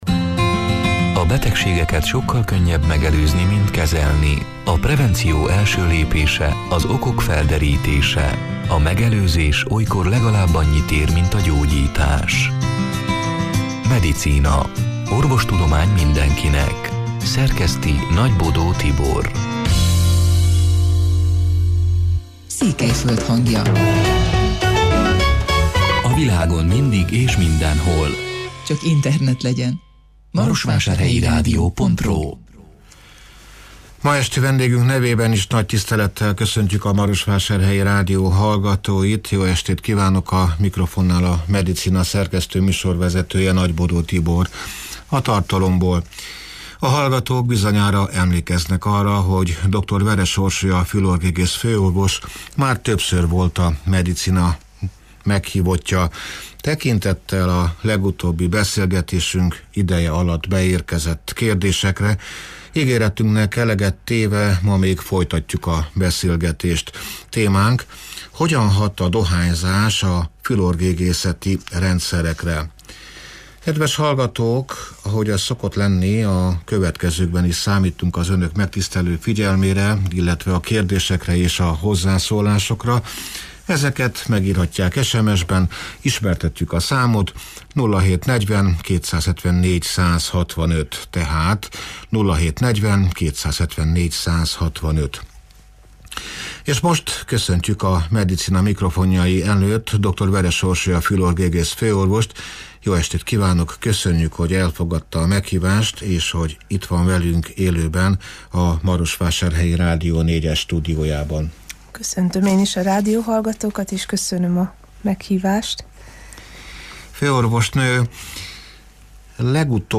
(elhangzott: 2025. április 23-án, szerdán este nyolc órától élőben)